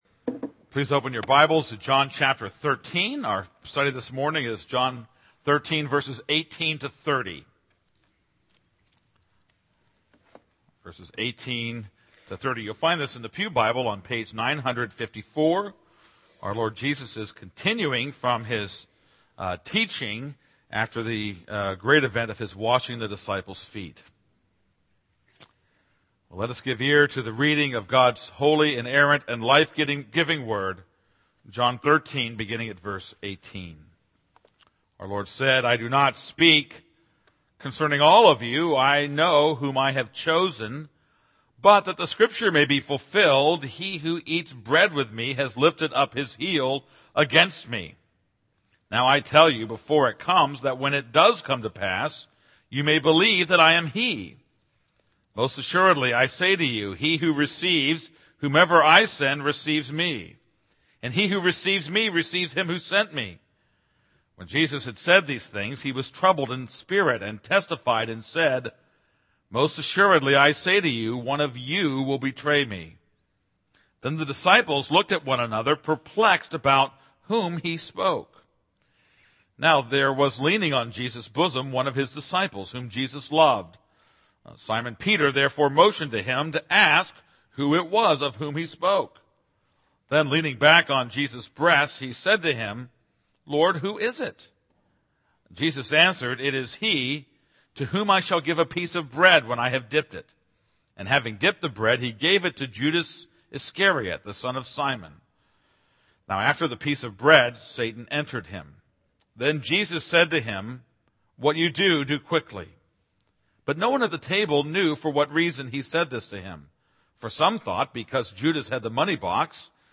This is a sermon on John 13:18-30.